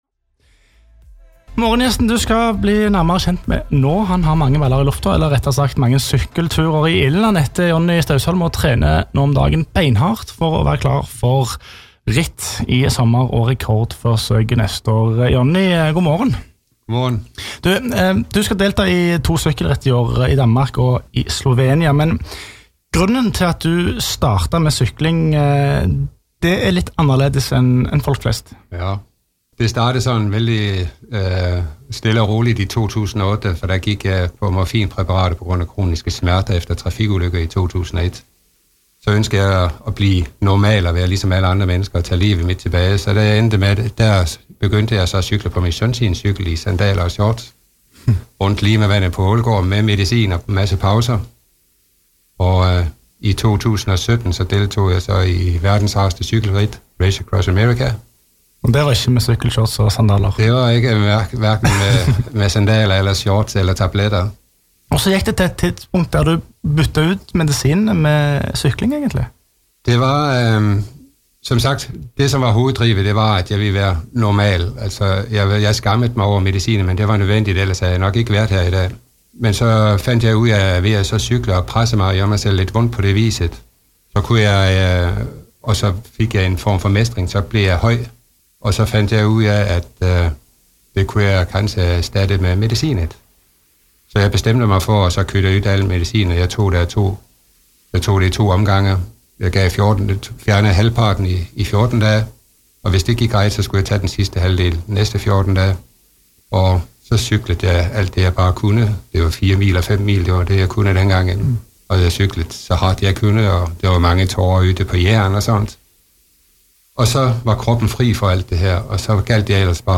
Intervju på JærRadioen del 1 - Intervju av meg.